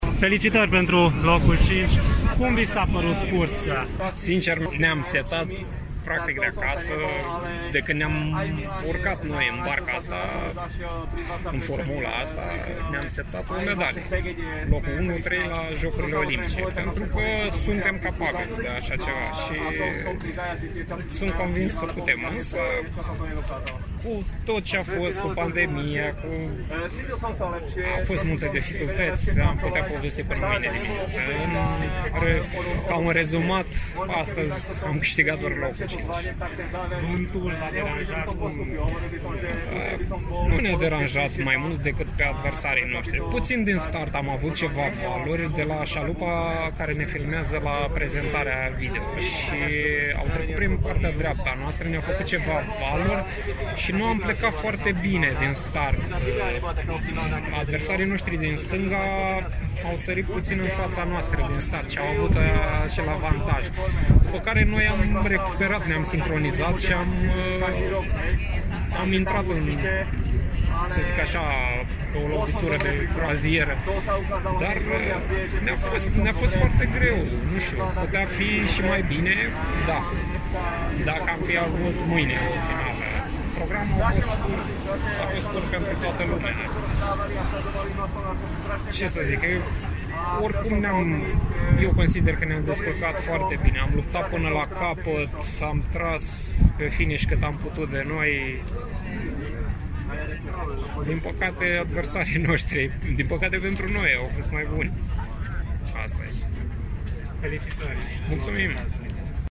Un interviu audio